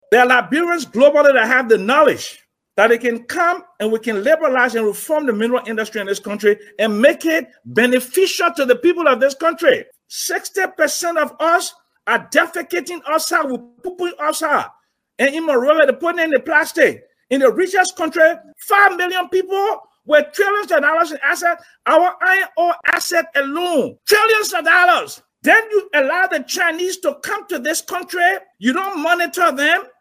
during a discussion on Spoon FM